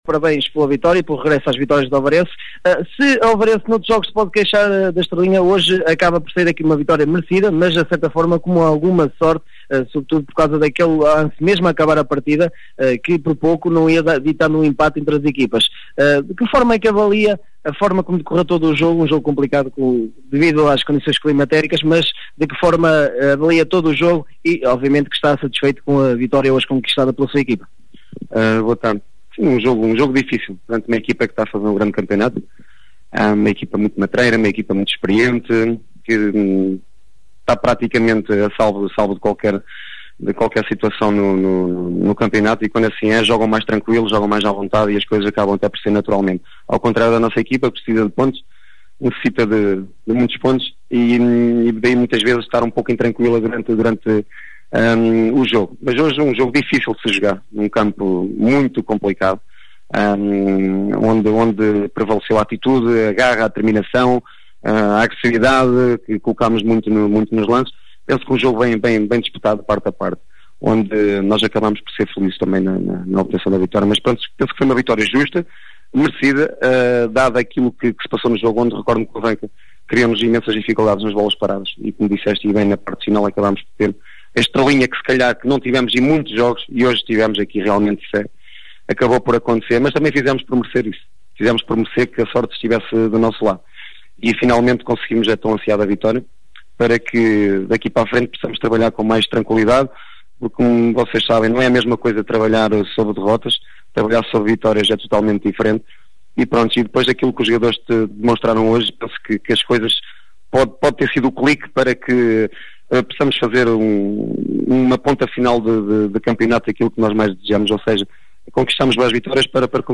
Ouça as declarações dos técnicos: